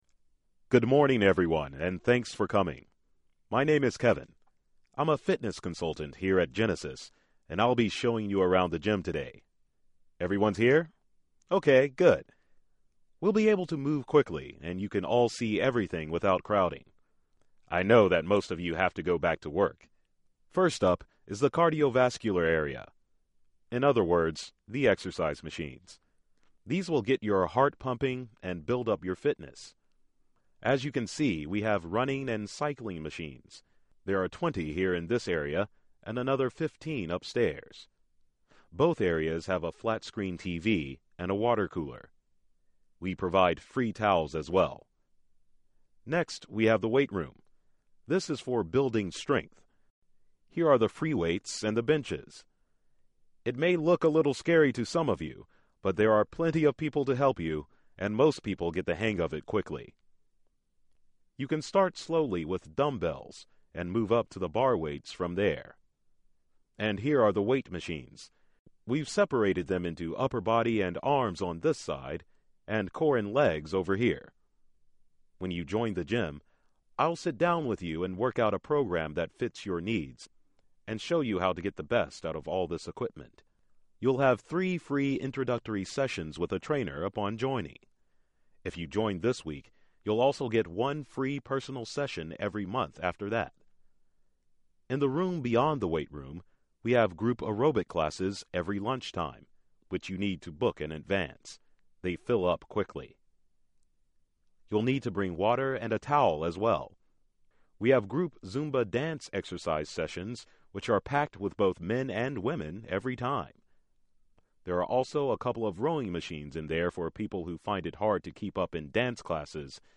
EFSET Sample Listening Section #2 (intermediate)